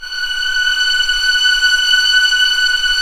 Index of /90_sSampleCDs/Roland LCDP13 String Sections/STR_Violins IV/STR_Vls7 p%f M